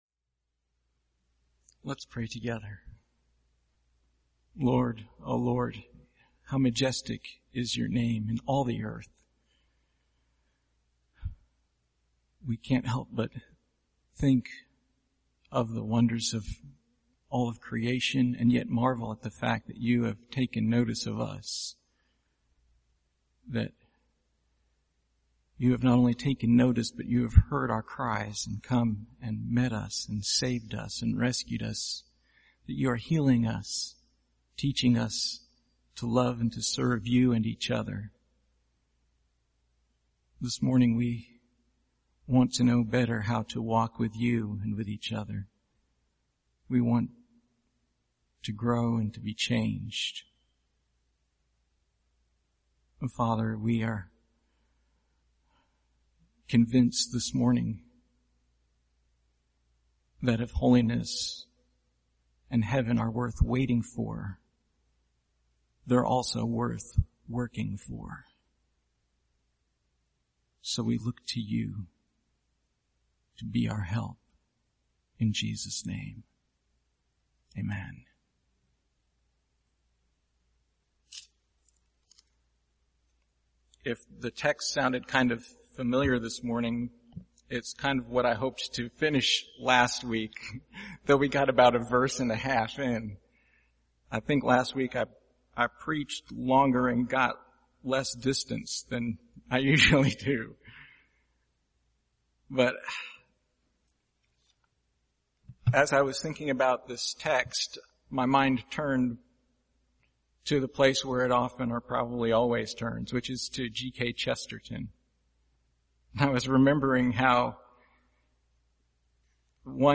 Passage: 1 Corinthians 15:23-28 Service Type: Sunday Morning